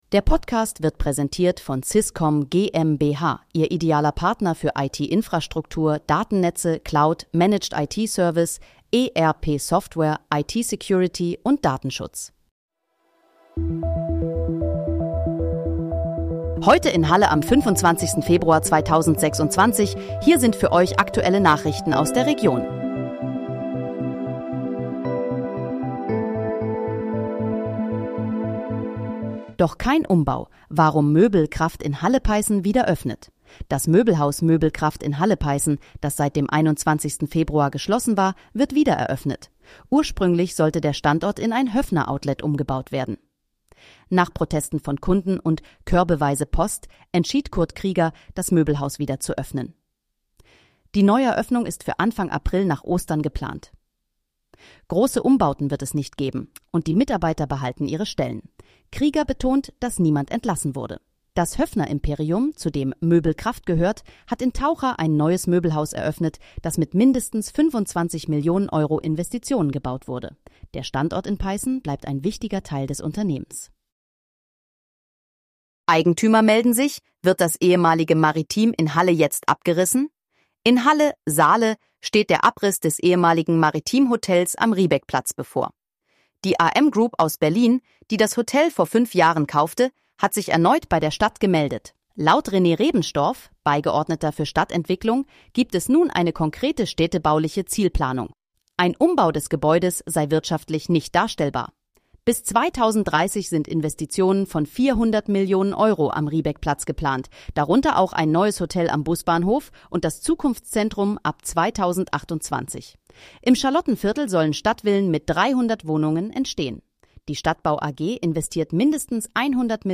Heute in, Halle: Aktuelle Nachrichten vom 25.02.2026, erstellt mit KI-Unterstützung